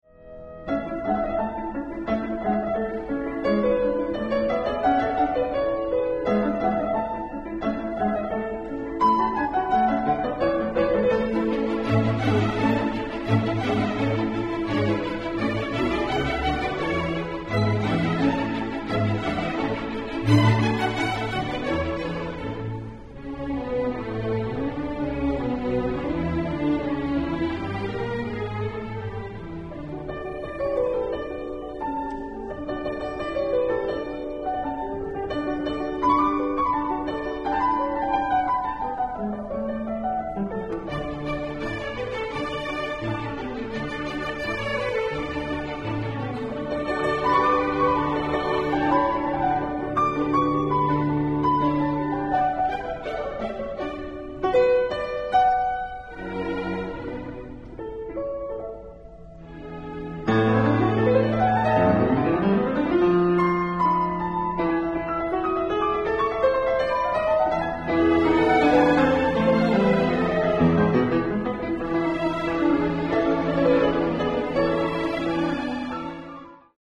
Concert for piano in A major
Live recording